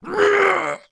fall_2.wav